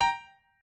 piano8_47.ogg